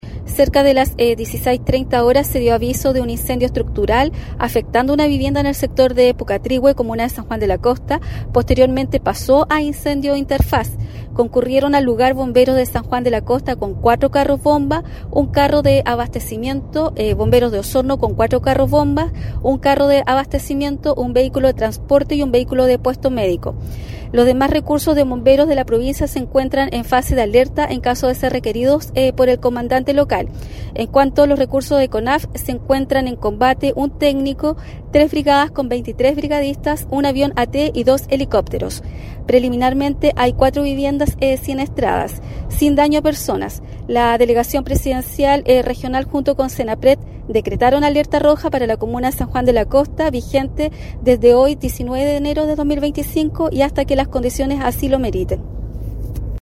La delegada presidencial Claudia Pailalef relató la labor realizada por los equipos de emergencia